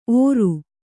♪ ōru